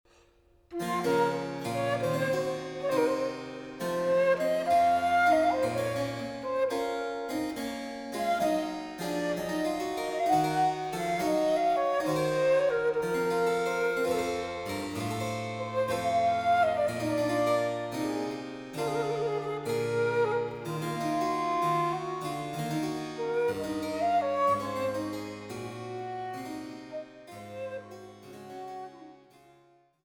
Allegrement